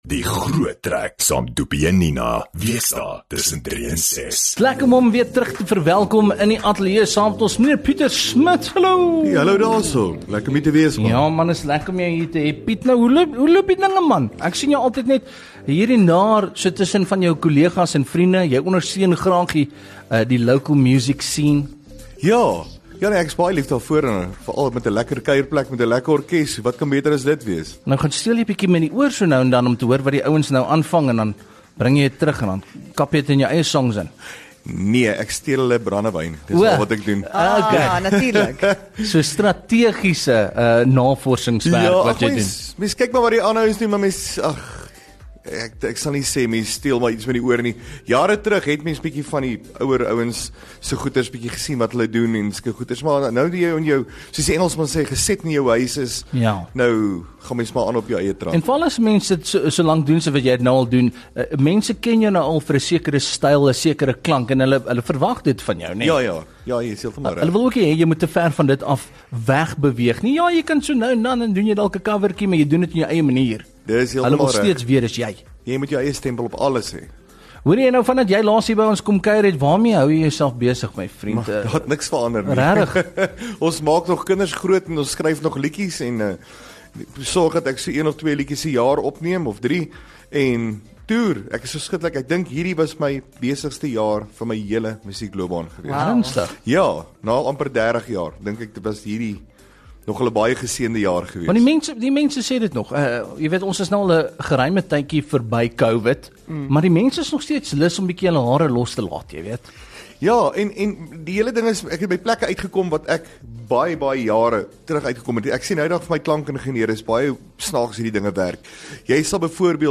kuier vandag saam ons in die ateljee en ons gesels bietjie oor name wat gebruik word in sy liedjies asook stereotipes en hoe hy dit maak werk in sy musiek.